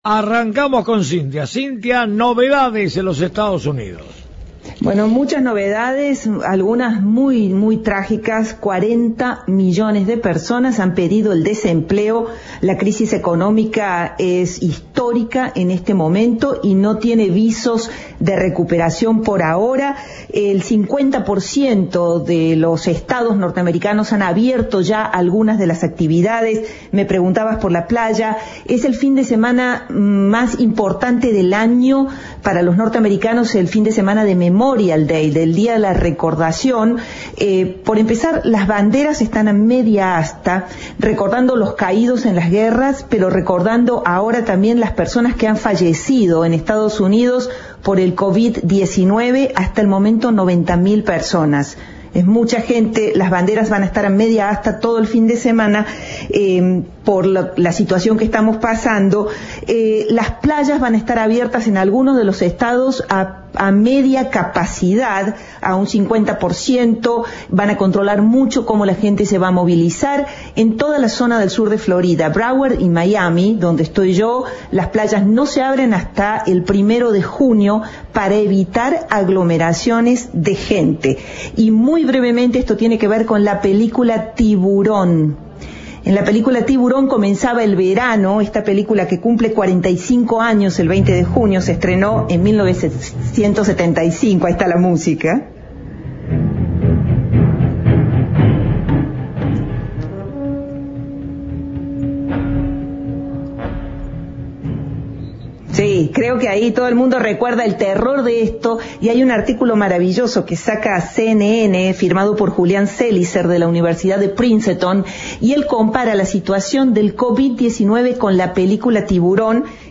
Audio. El desempleo alcanzó el 10,3%